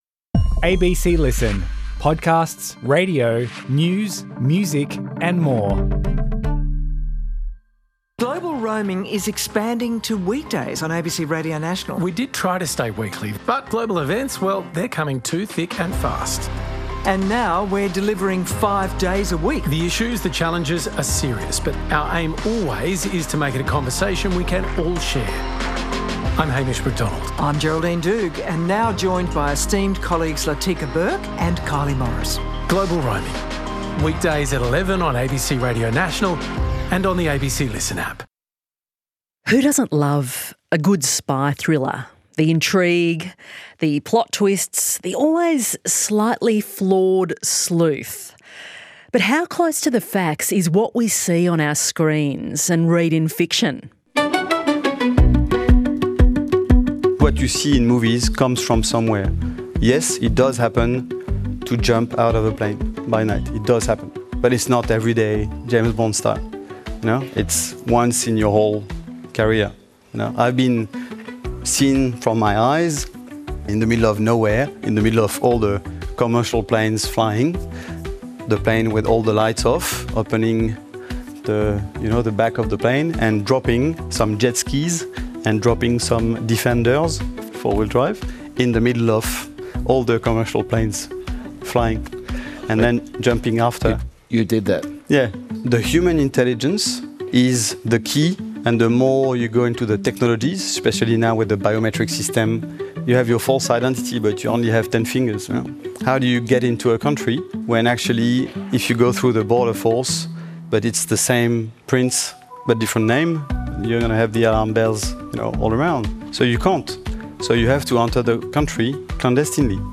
Spies, Lies and Secretes was recorded live at the 2025 Sydney Writers’ Festival at Carriageworks.